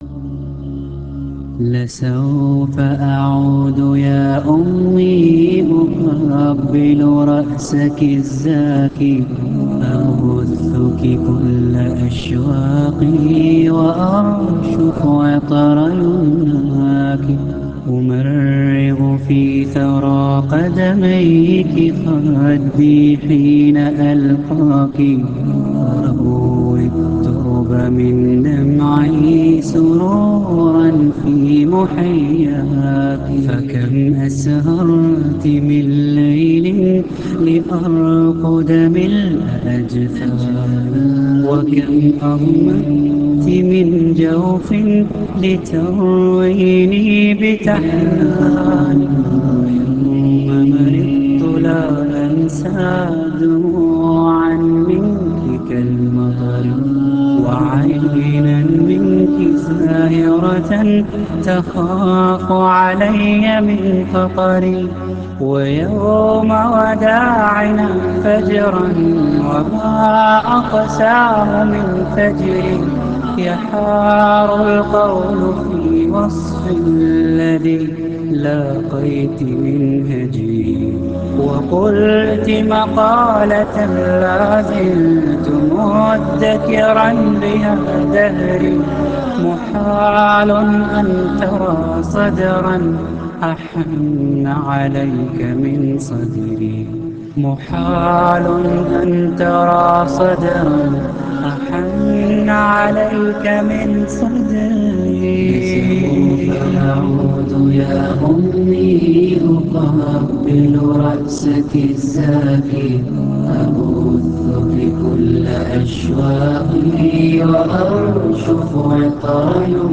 naat mp3 play online & download.
in a Heart-Touching Voice